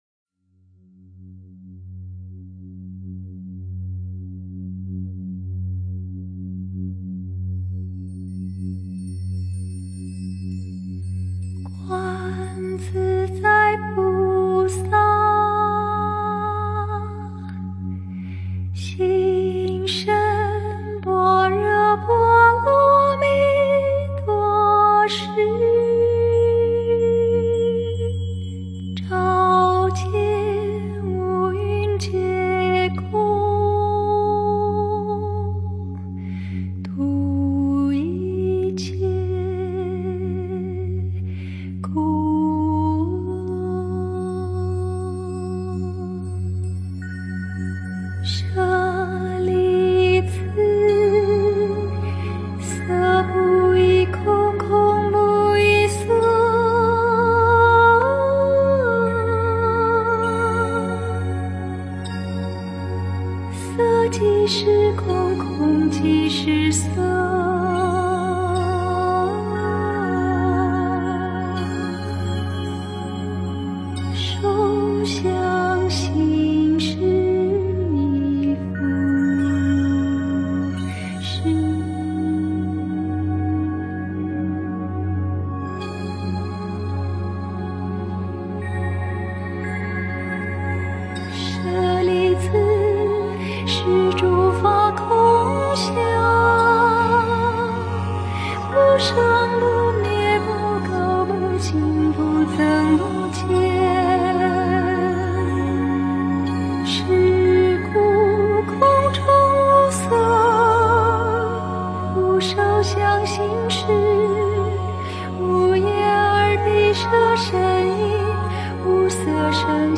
佛音 诵经 佛教音乐 返回列表 上一篇： 观音普门品 下一篇： 发殊胜心 相关文章 忿怒莲师冈梭(一